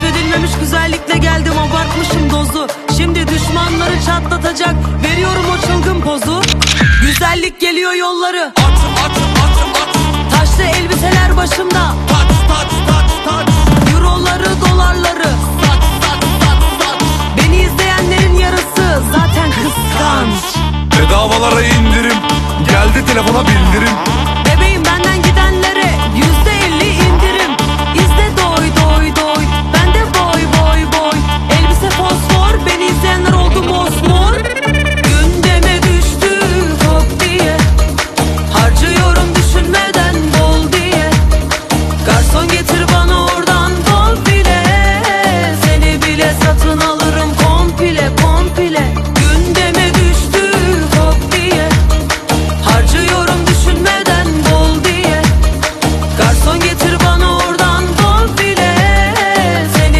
Bmw F30 Valvetronic Egzoz çatara Sound Effects Free Download